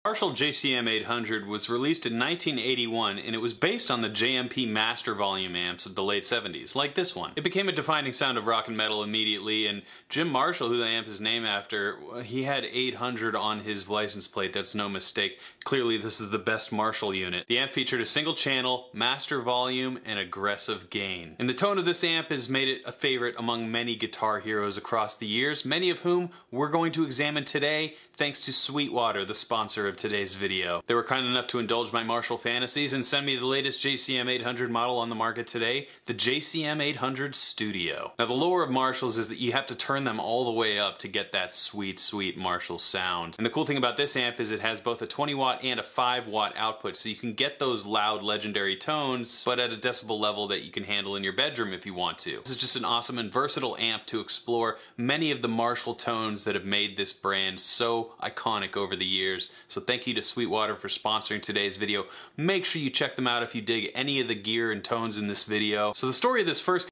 10 Legendary Guitar Tones That sound effects free download
10 Legendary Guitar Tones That ARE 'The Marshall Sound'